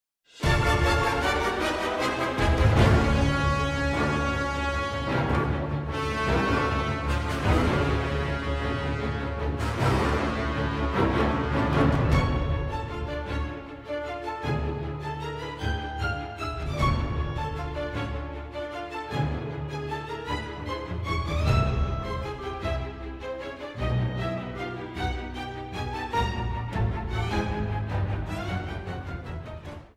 soundtrack
Trimmed to 30 seconds, with a fade out effect